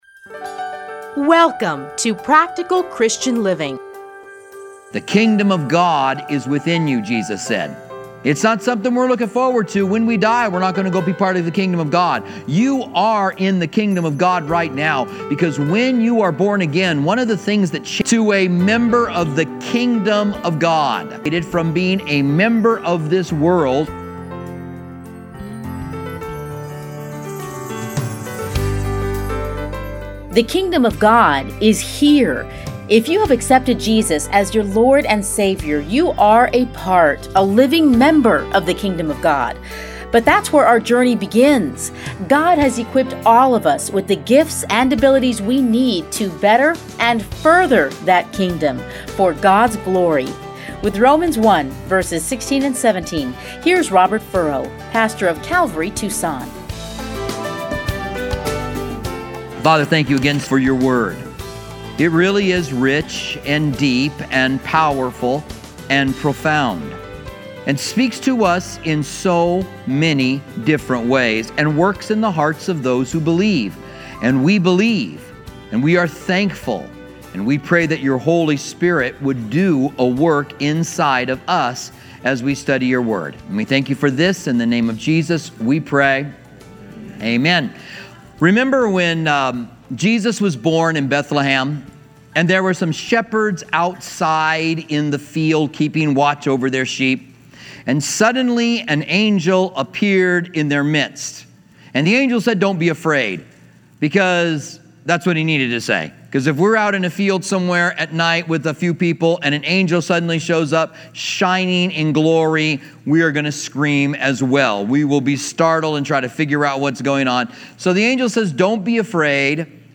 Listen here to his commentary on Romans.